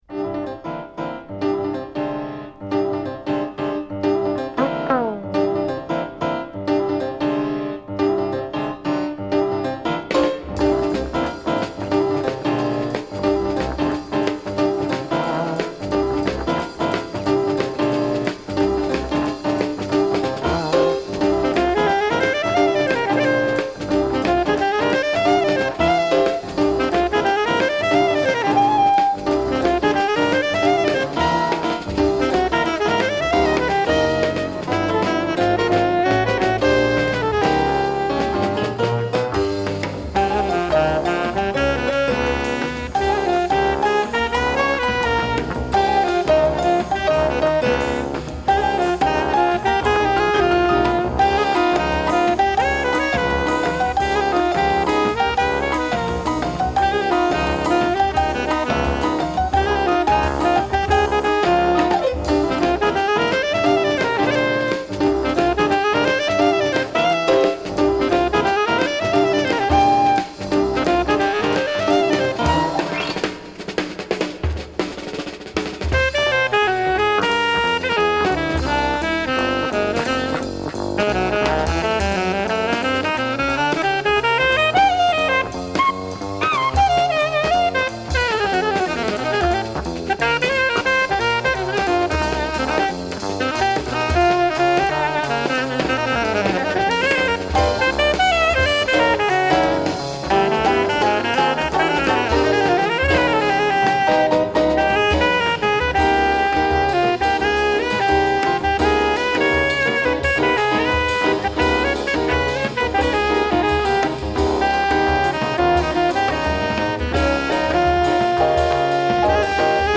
alto saxophone/flute*
piano
bass
drums
Recorded: February at RG. Studio, Vicenza, Italy